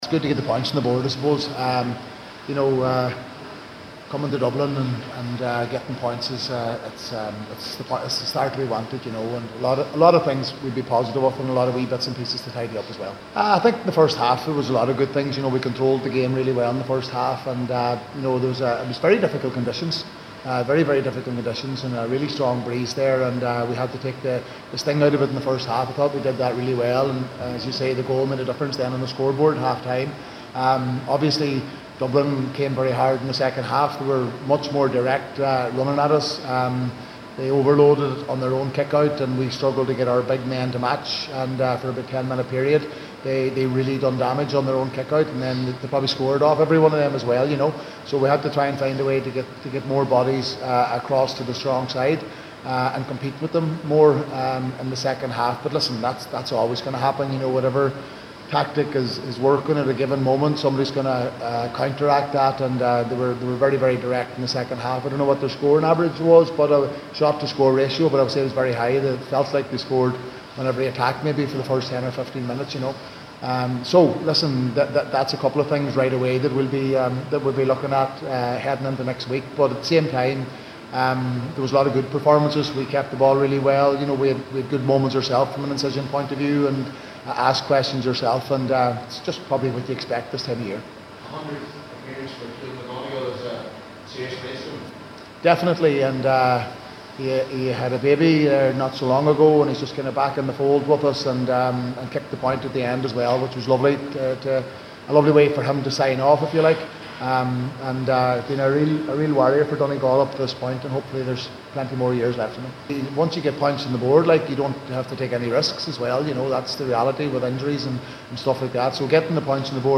Donegal manager Jim McGuinness
After the game, McGuinness said it was “good to get points on the board” when he spoke to the assembled media.